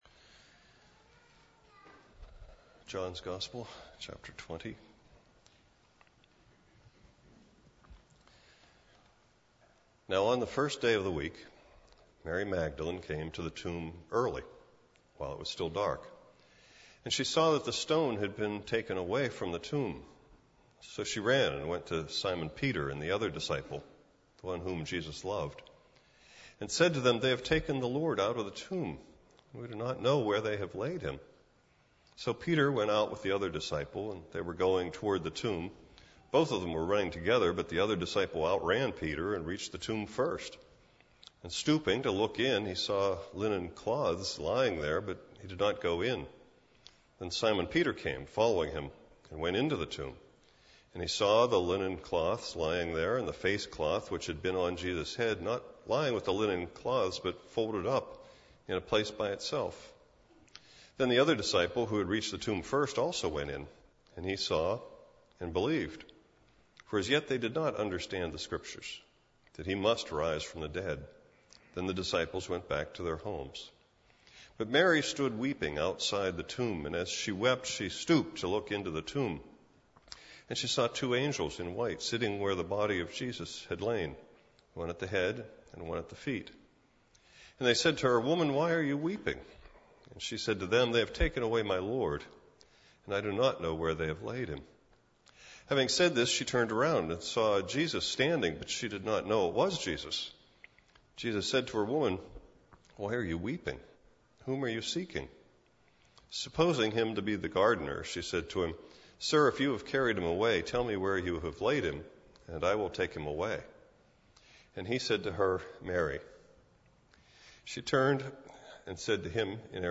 And Easter Sunday.